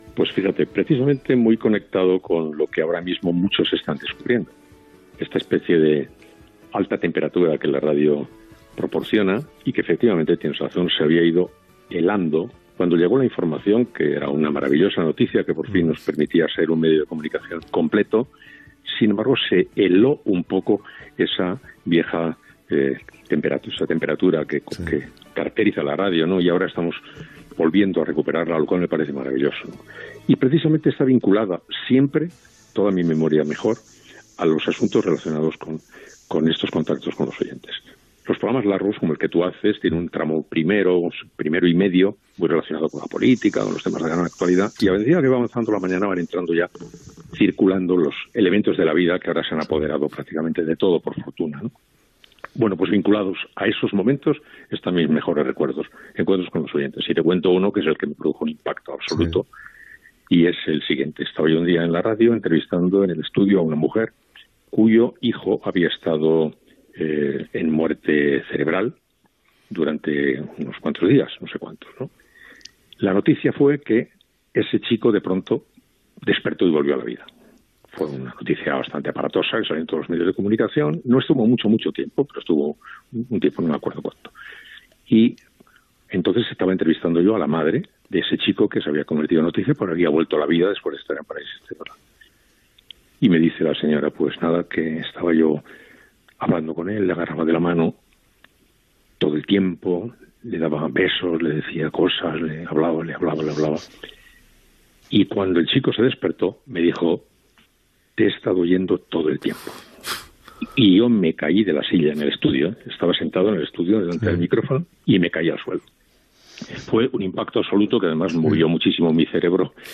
Homenatge a la ràdio en temps de Covid. Fragments d'entrevistes a:
Info-entreteniment